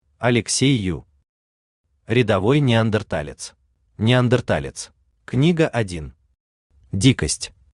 Аудиокнига Рядовой неандерталец | Библиотека аудиокниг
Aудиокнига Рядовой неандерталец Автор Алексей Ю Читает аудиокнигу Авточтец ЛитРес.